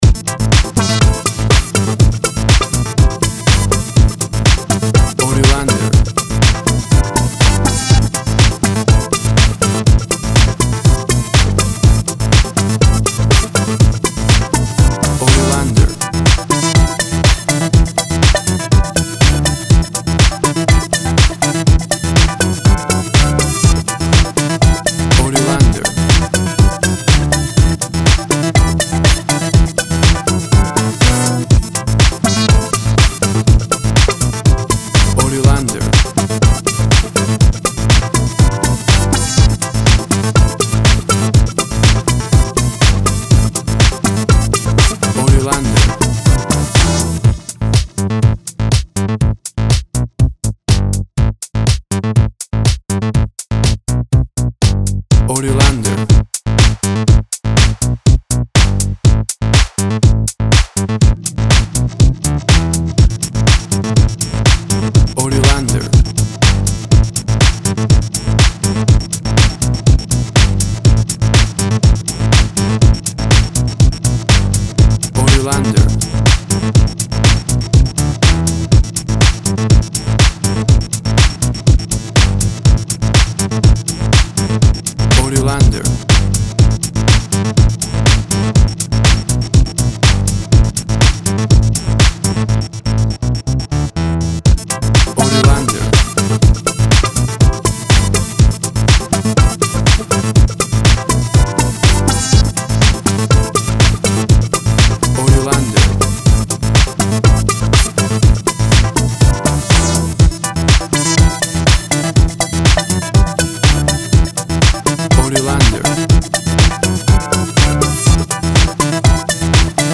Electro funky house music.
Tempo (BPM) 122